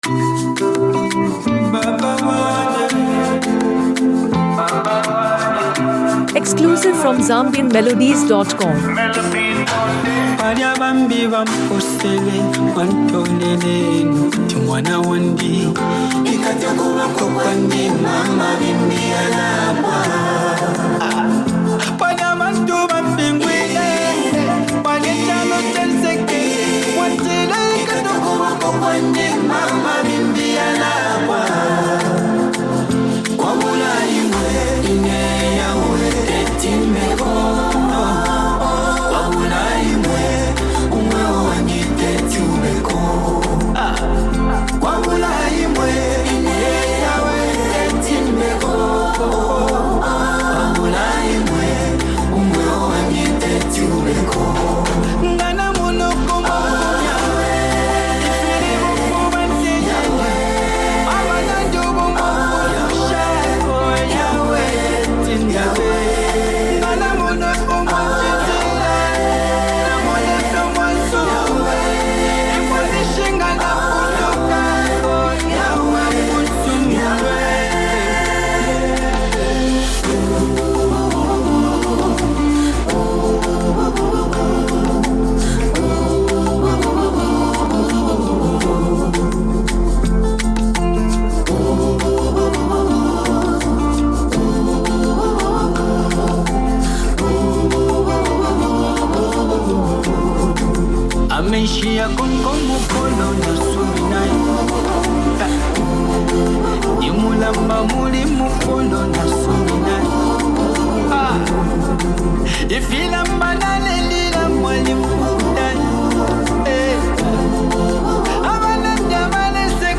Inspirational Gospel Song
Gospel Music Zambia